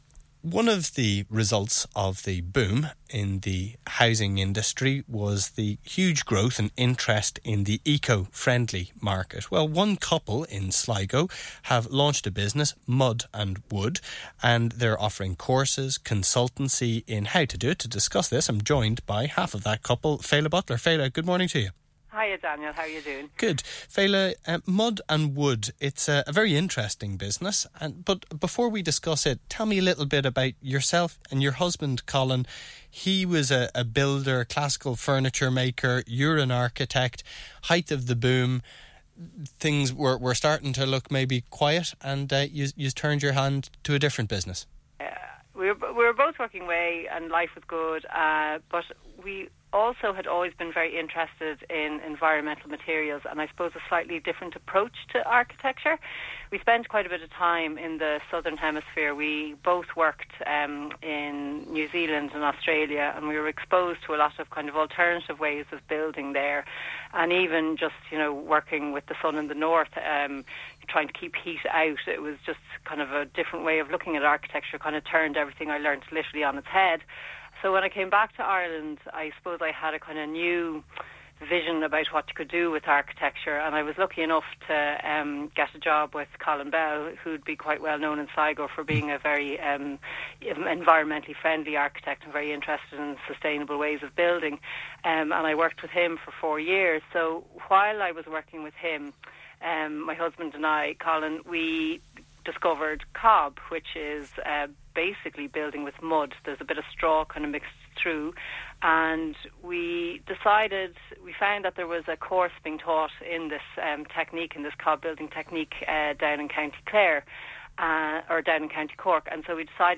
A month ago, I was interviewed on Ocean FM's business show - getting the chance to talk about how we discovered cob in the first place and how we are turning our passion into a business.